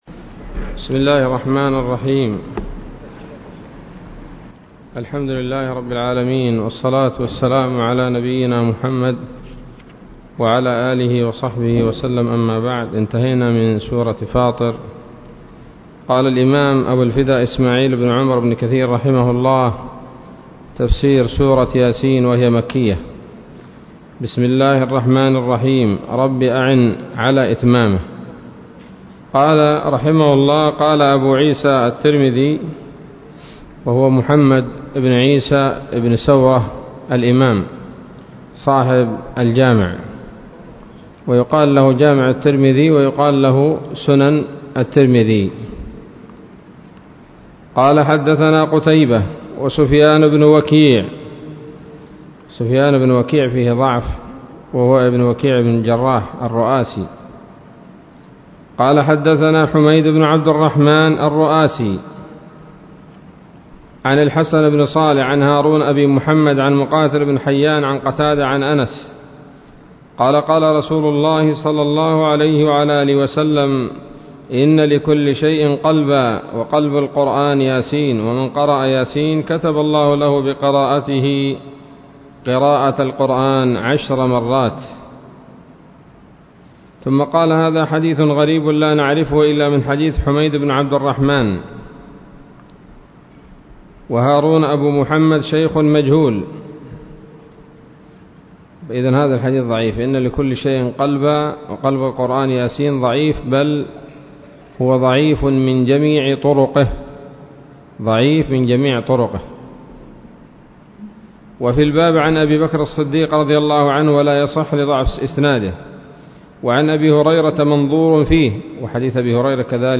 الدرس الأول من سورة يس من تفسير ابن كثير رحمه الله تعالى